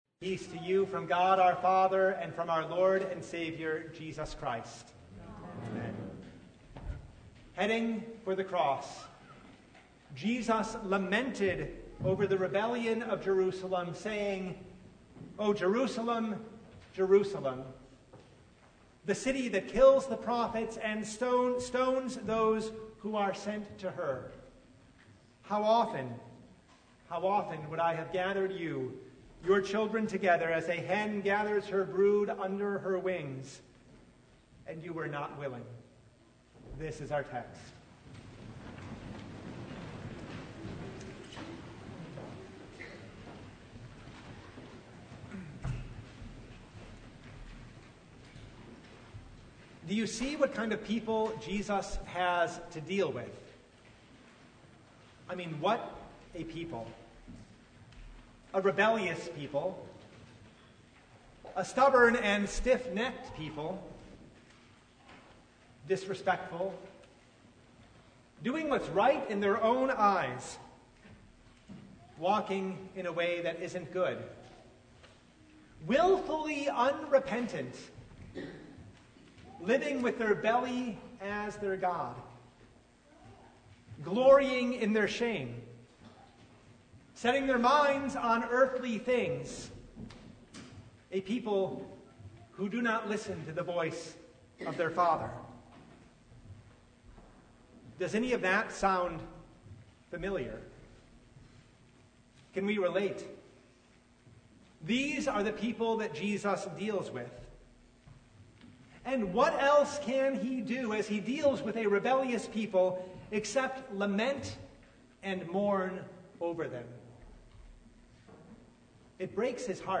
Luke 13:31-35 Service Type: Sunday For a rebellious people